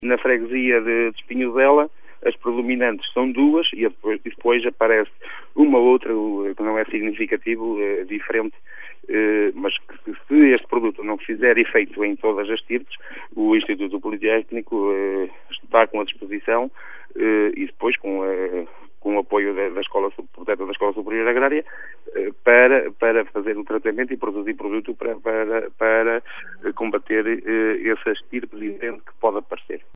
O remédio só é disponibilizado após a identificação das estirpes de cancro, o que aconteceu no caso de Espinhosela, como adiantou o presidente da junta de freguesia, Telmo Afonso.